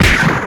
slapstickSuperPunch.ogg